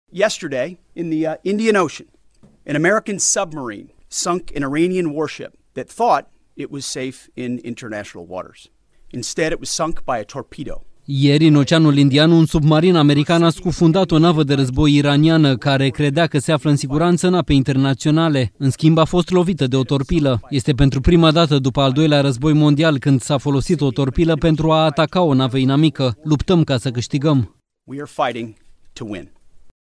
04mar-16-Pete-Hegseth-despre-NAVA-TRADUS-.mp3